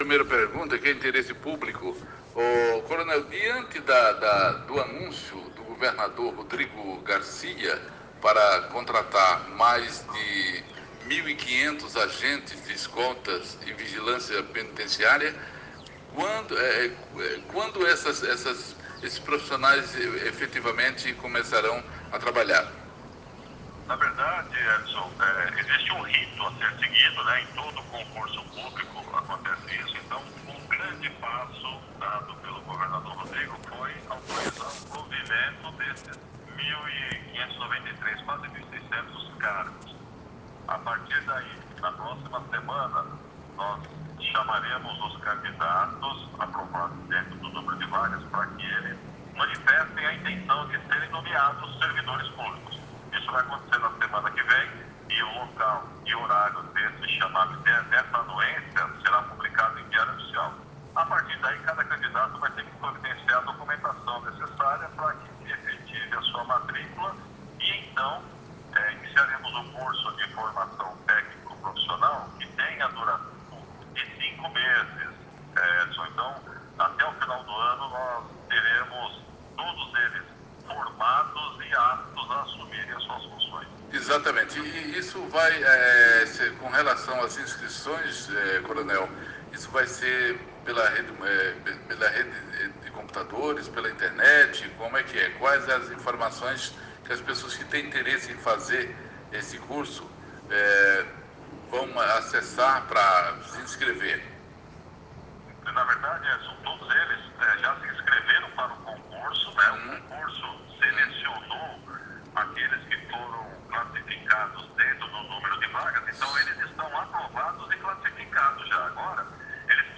Em entrevista a radio Nova Estação FM Secretário Restivo confirma informação do SINDESPE de que chamadas ocorrerão na semana que vem.
Áudio da entrevista abaixo: